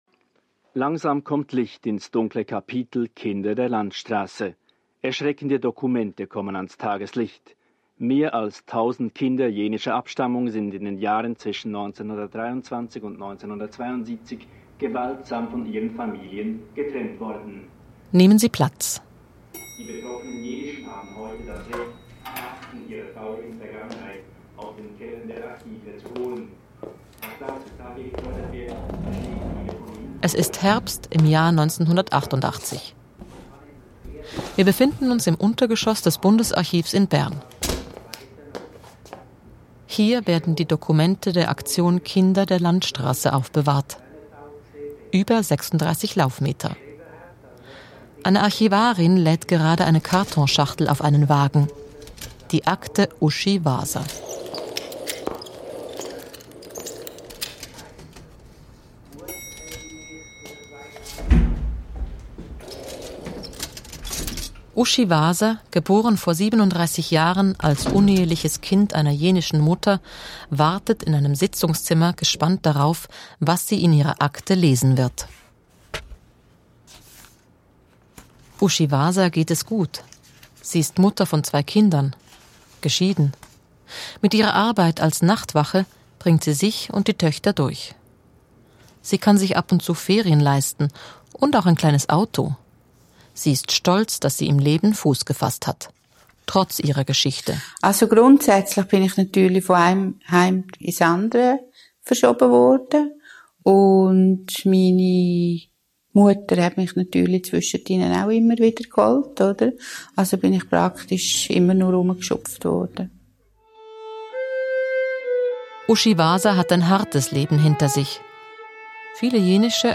Hörspiele und Archivdokumente erzählen die Schicksale.